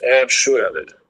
Text-to-Speech